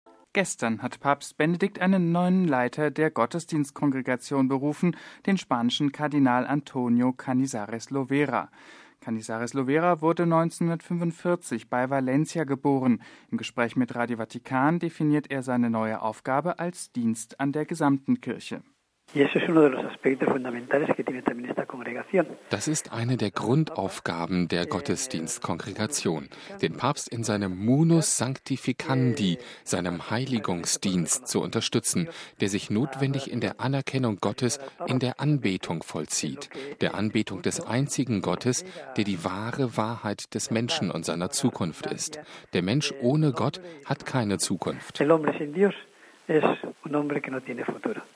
Interview mit Kardinal Canizares